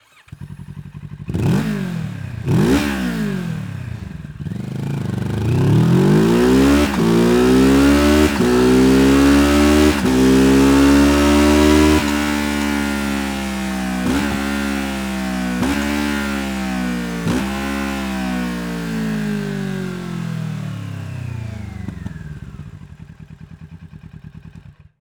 Ein tieferer und sportlicherer Sound weckt noch mehr Begehrlichkeiten.
Sound Akrapovic Komplettanlage Z650